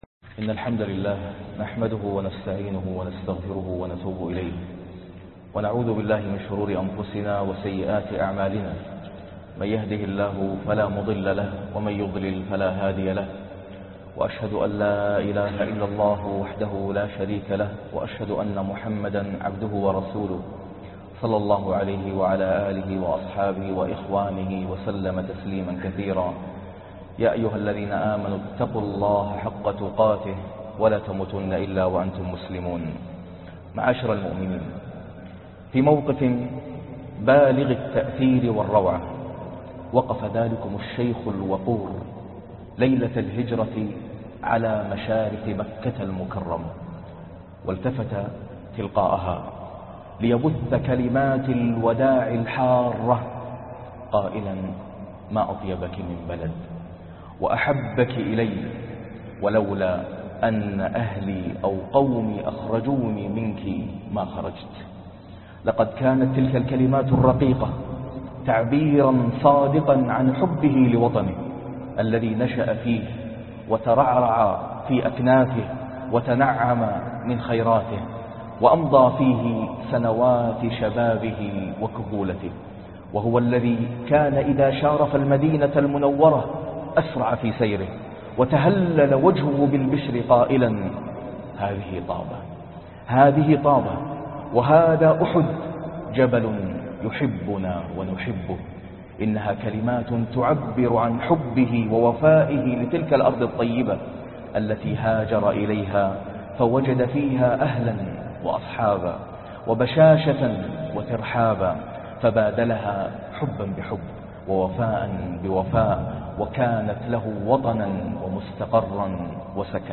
التأصيل الشرعي لفقه الإنتماء والمواطنة - خطبة الجمعة